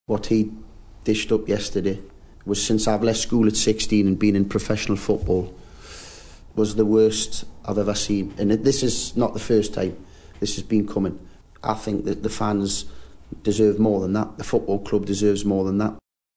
Birmingham striker Nikola Zigic has been dropped from the Blue's matchday squad for tomorrow's Championship game with Watford because of a poor performance in training. His manager Lee Clark was heavily critical at his press conference today.